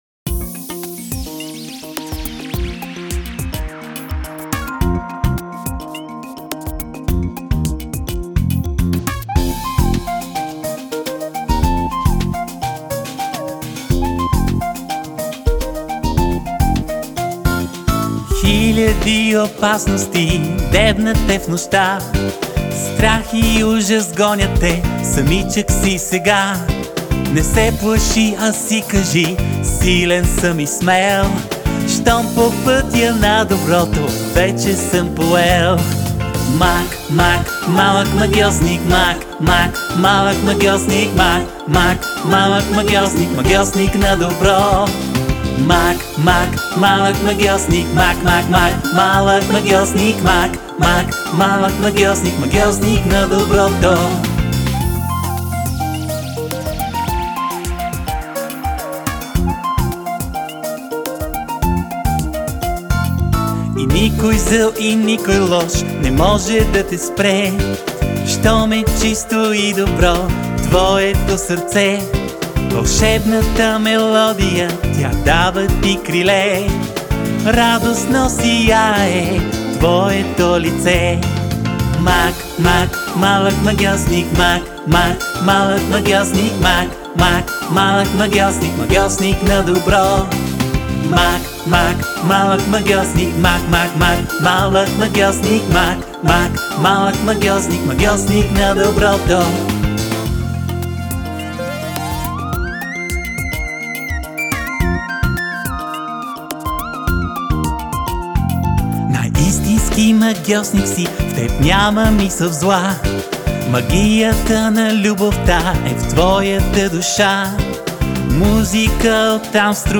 20 авторски детски песнички